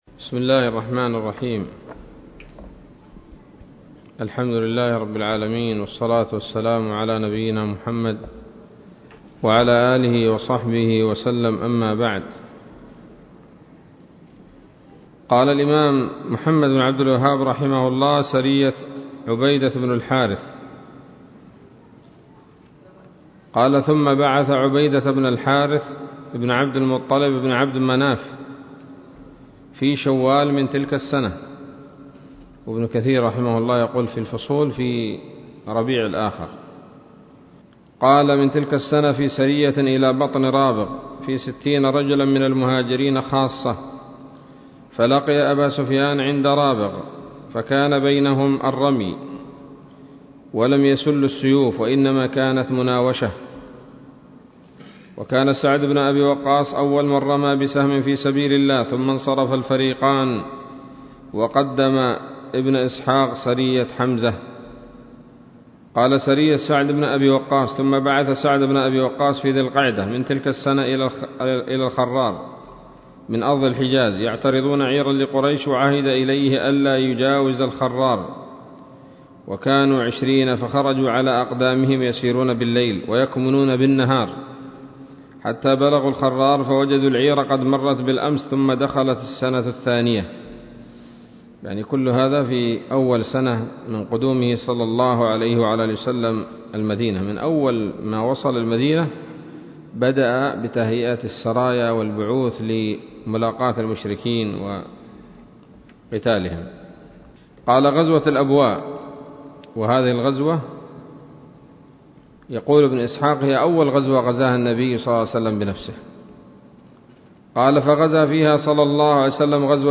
الدرس الخامس والثلاثون من مختصر سيرة الرسول ﷺ